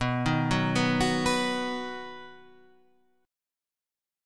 B Major